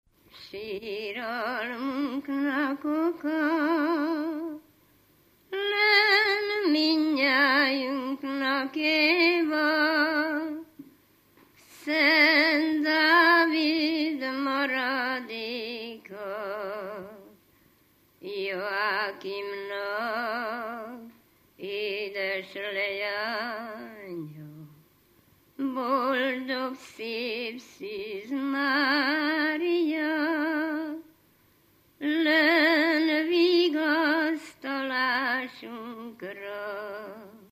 Erdély - Udvarhely vm. - Lövéte
ének
Stílus: 7. Régies kisambitusú dallamok
Kadencia: 5 5 (1) V 5 1